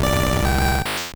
Cri d'Artikodin dans Pokémon Or et Argent.